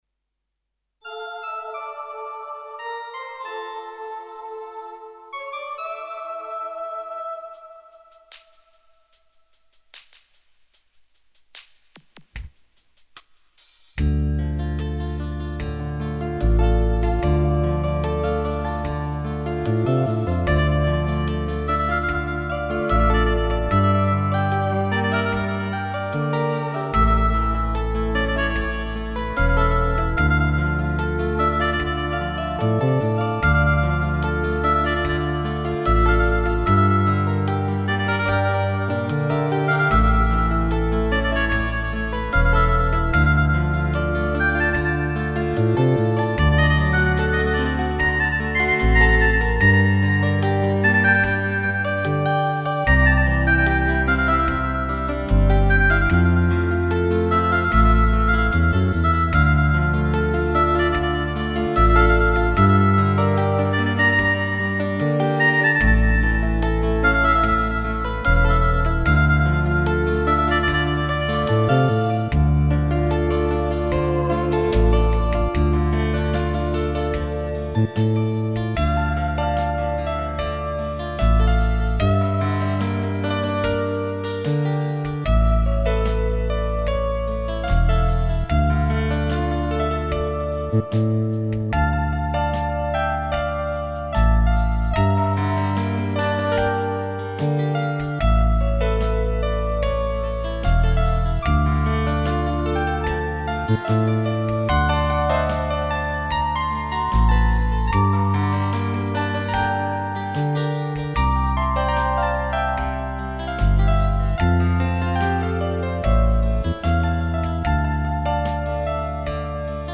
Про слова не могу сказать, наверное инструментал.